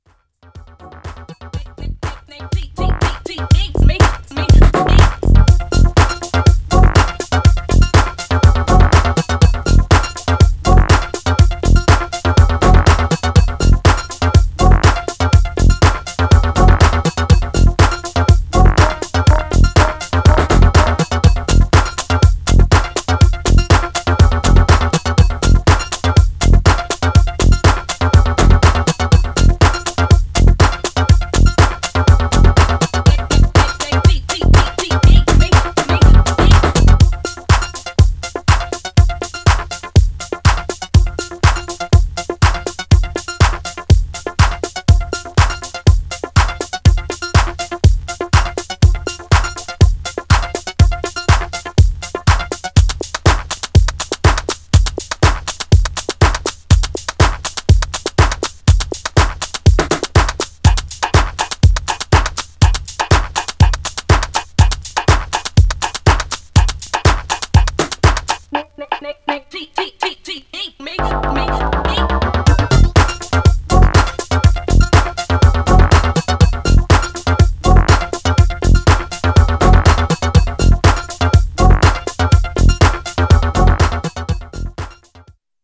Styl: Electro, House, Techno, Breaks/Breakbeat